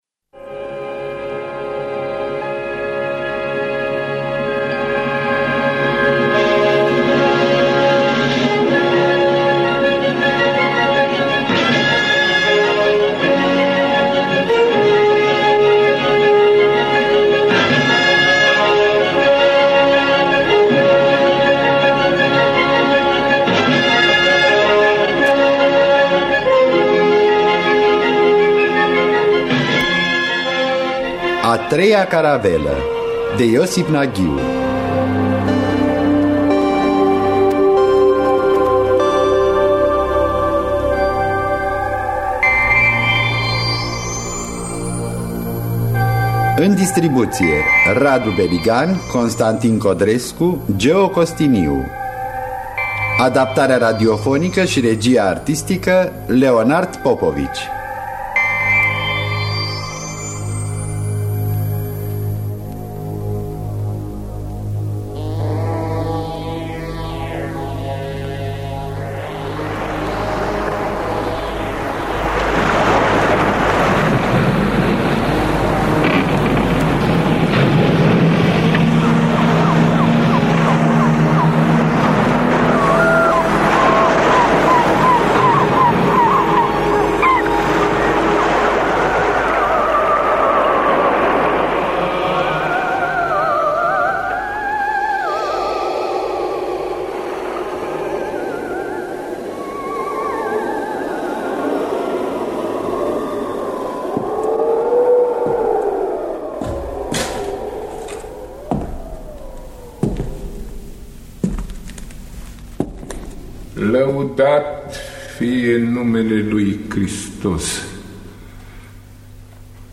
Iosif Naghiu – A Treia Caravela (1992) – Teatru Radiofonic Online